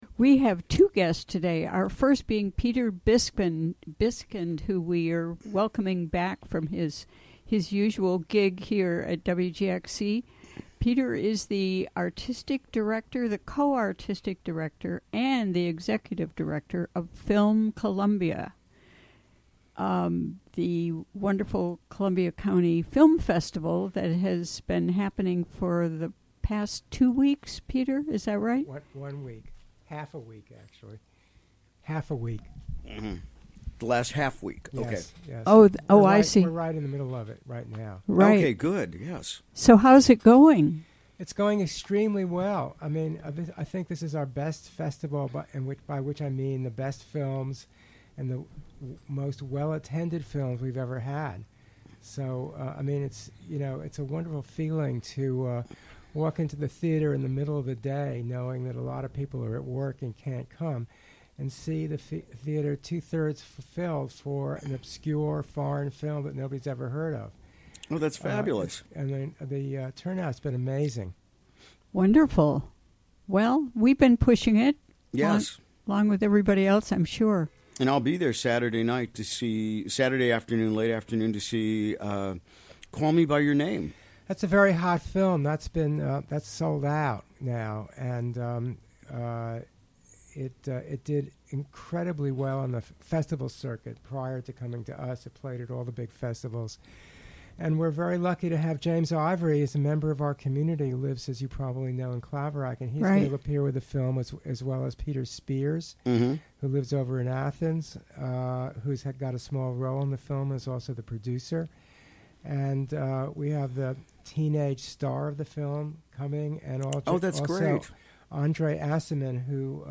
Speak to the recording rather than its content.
Recorded during the WGXC Afternoon Show on October 26, 2017.